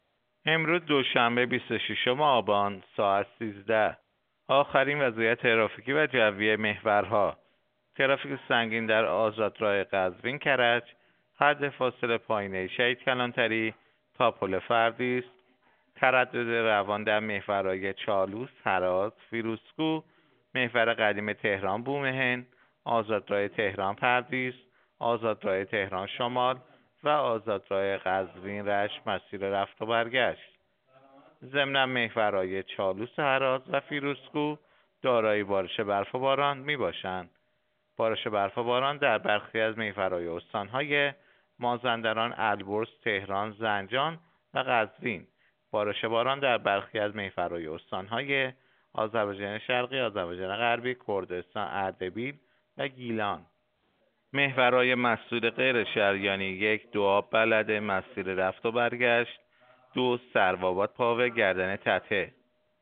گزارش رادیو اینترنتی از آخرین وضعیت ترافیکی جاده‌ها ساعت ۱۳ بیست و ششم آبان؛